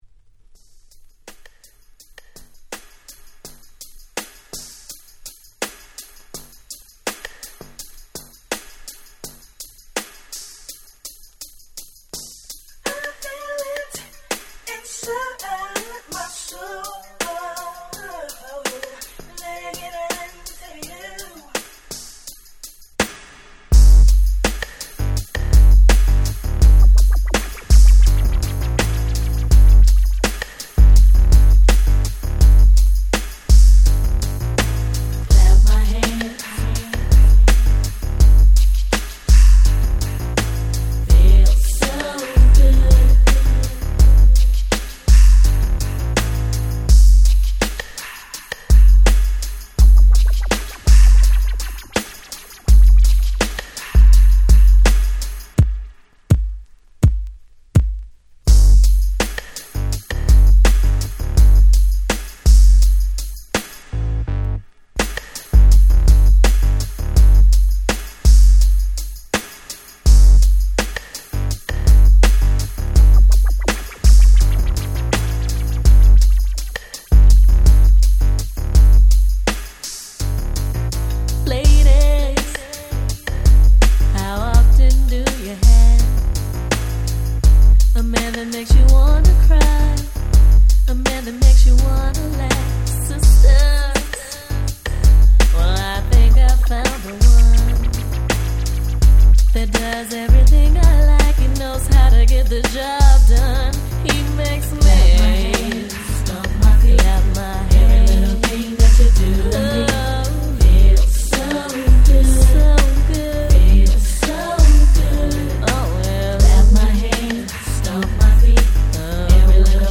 95' Smash Hit R&B !!
決して派手さは無いものの、彼女達のコーラスワークを十二分に堪能出来る素晴らしいHip Hop Soul